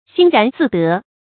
欣然自得 xīn rán zì dé
欣然自得发音
成语注音ㄒㄧㄣ ㄖㄢˊ ㄗㄧˋ ㄉㄜˊ